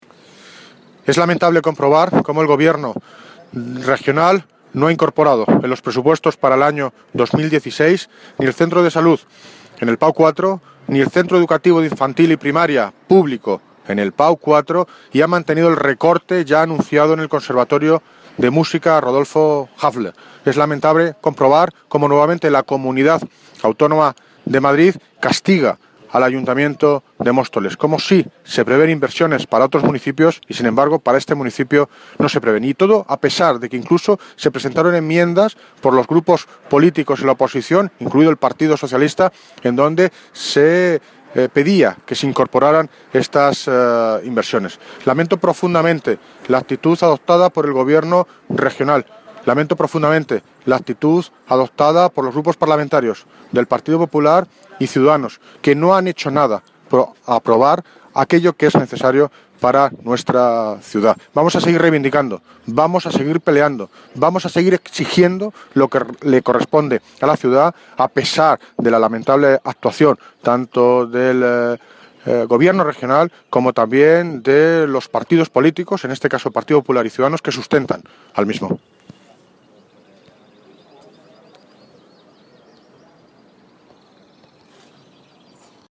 David Lucas (Alcalde de Móstoles) sobre presupuestos CAM